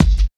99 KICK 6.wav